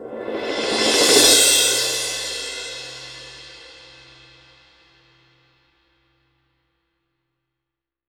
01 CYMBAL SW.wav